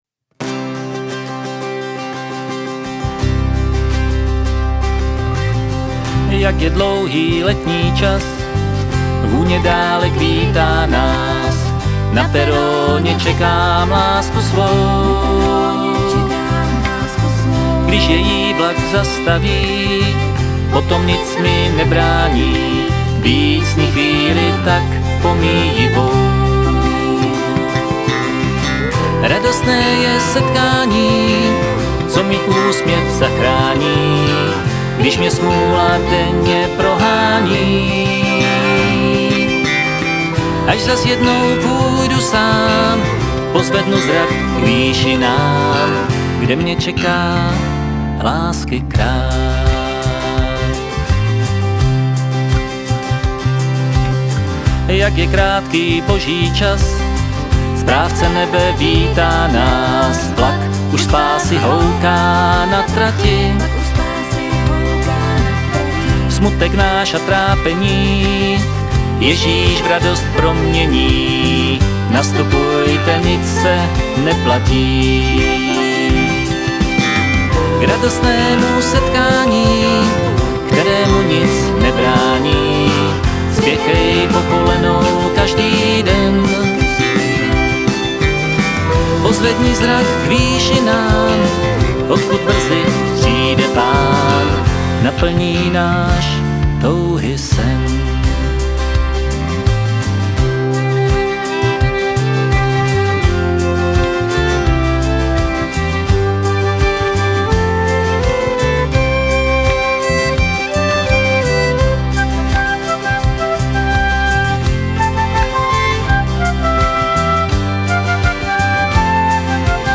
v refrénu "baskytarová etuda", za mě celkem zajímavá linka